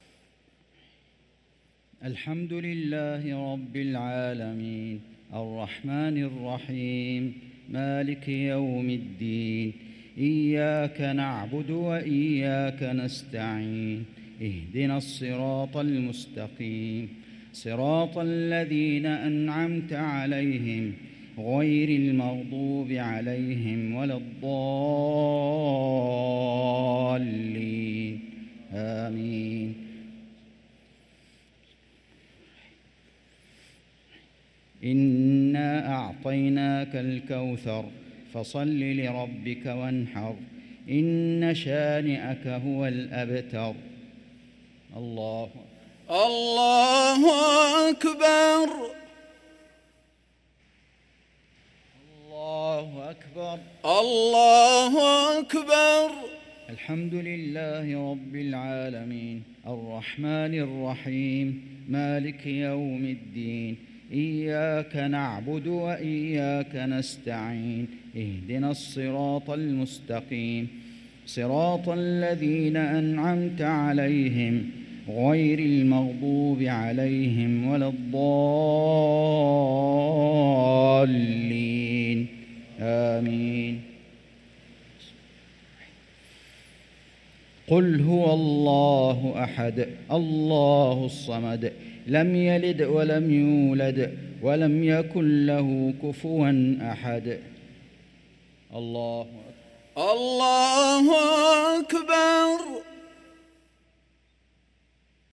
صلاة المغرب للقارئ فيصل غزاوي 12 ربيع الآخر 1445 هـ
تِلَاوَات الْحَرَمَيْن .